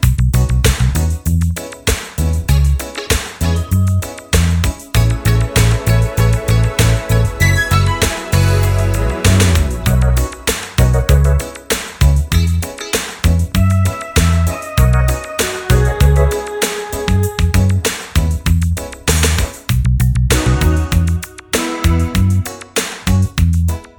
no Backing Vocals Reggae 3:24 Buy £1.50